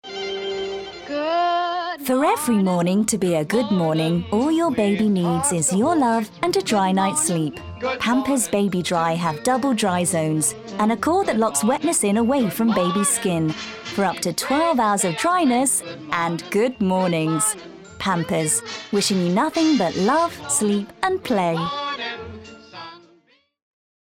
30/40's Neutral/RP, Warm/Versatile/Bright
Commercial Showreel